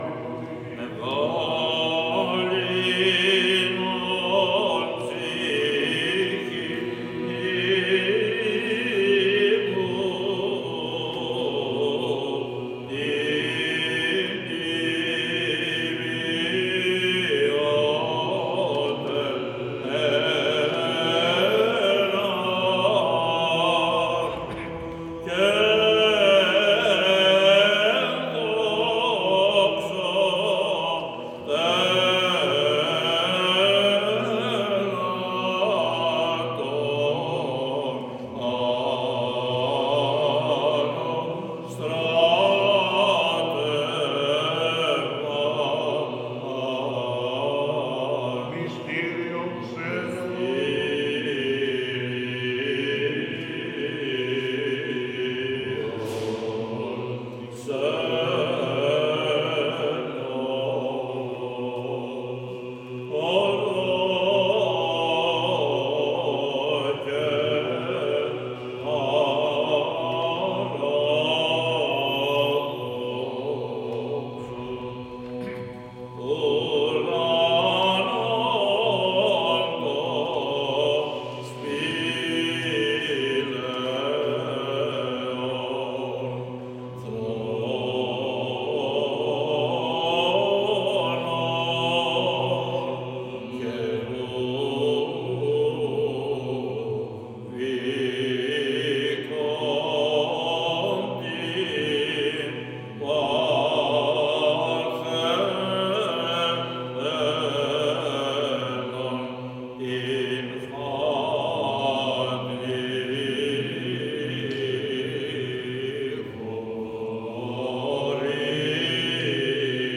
Ἀγρυπνία Χριστουγέννων 2020
Τήν ἱερή νύχτα τῶν Χριστουγέννων, μετά τό πέρας τοῦ ἱεροῦ Σαρανταλείτουργου πού μέ εὐλάβεια τελέστηκε τήν περίοδο αὐτή, ἑορτάστηκε στήν Ἱερά Μονή Ξενοφῶντος μέσα σέ κατανυκτική ἀτμόσφαιρα τό μέγα μυστήριο τῆς ἐνανθρωπήσεως τοῦ Λόγου τοῦ Θεοῦ.
Οἱ θεόπνευστοι ὕμνοι τῶν Ἁγίων ὑμνογράφων τῆς Ἐκκλησίας μας, κατέκλυσαν τόν περίλαμπρο ναό τοῦ Ἁγίου Γεωργίου ὁδηγώντας τούς πατέρας εἰς πνευματικάς ἀναβάσεις καί εἰς κοινωνίαν μετά τοῦ τεχθέντος Σωτῆρος Χριστοῦ καί τοῦ πληρώματος τῶν πιστῶν τῆς Ἐκκλησίας.